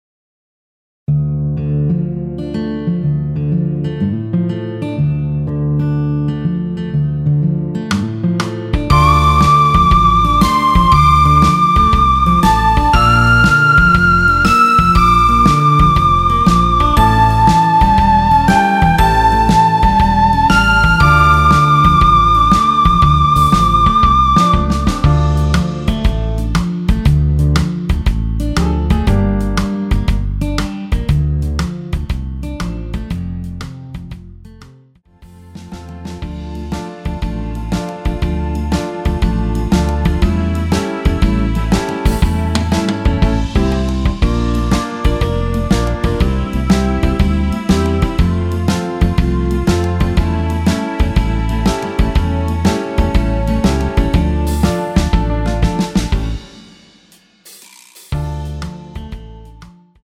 엔딩이 페이드 아웃으로 끝나서 라이브에 사용하실수 있게 엔딩을 만들어 놓았습니다.
원키에서(-2)내린 MR입니다.
Dm
앞부분30초, 뒷부분30초씩 편집해서 올려 드리고 있습니다.